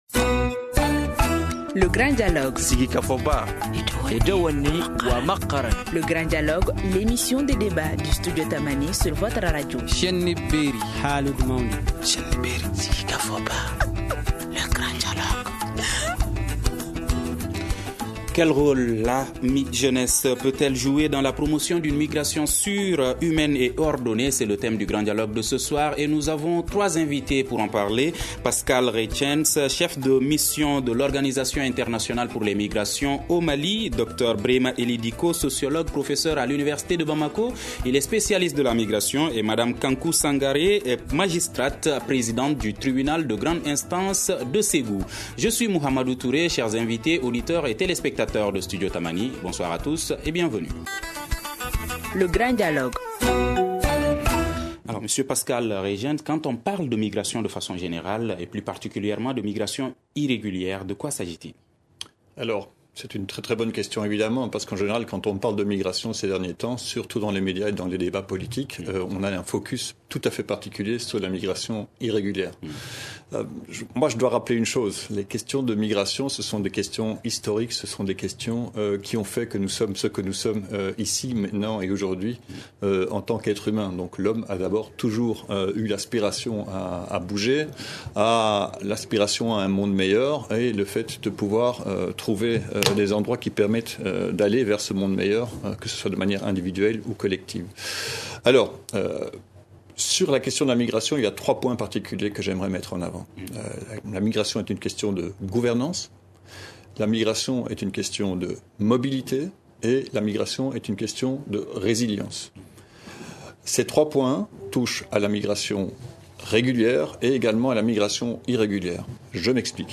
Quel rôle la jeunesse peut-elle jouer pour l’atteinte de ce objectif ?Le Grand Dialogue pose le débat sur ces questions.